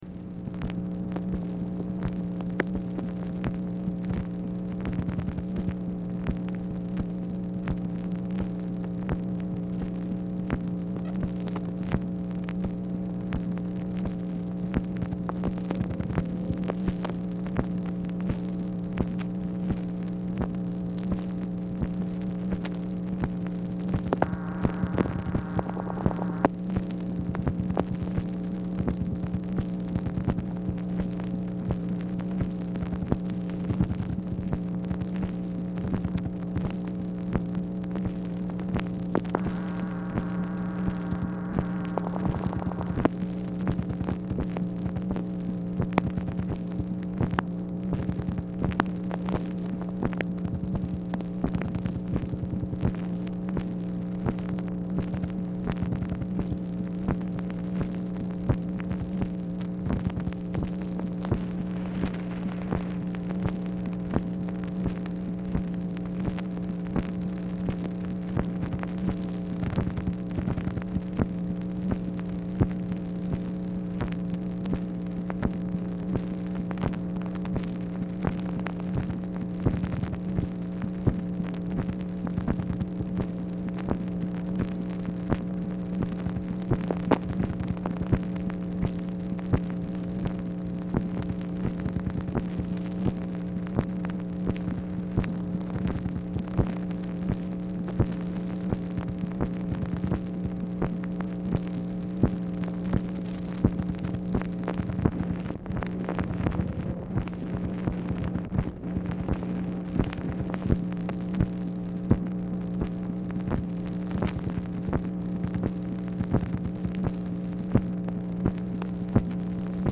Telephone conversation # 8393, sound recording, MACHINE NOISE, 7/26/1965, time unknown
MACHINE NOISE
Oval Office or unknown location
Telephone conversation
Dictation belt